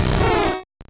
Cri de Minidraco dans Pokémon Rouge et Bleu.